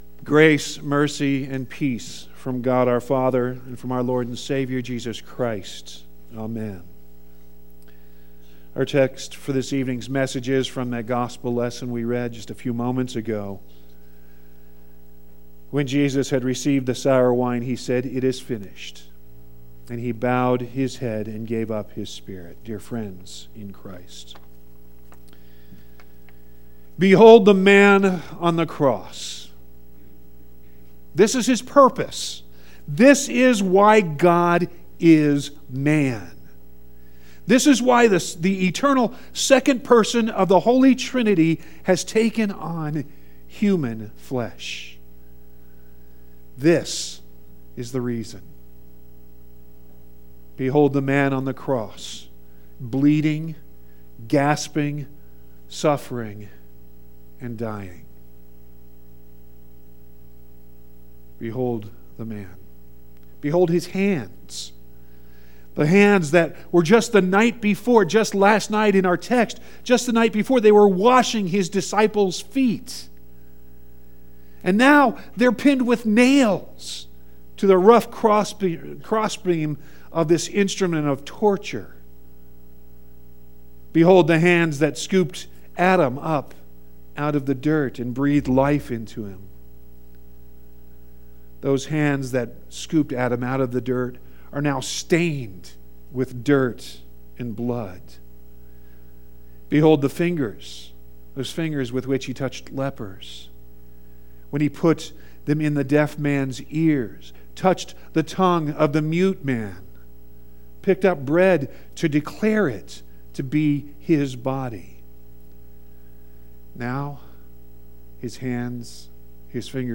You can also download the sermon directly HERE, or get all the sermons on your phone by subscribing to our Podcast HERE.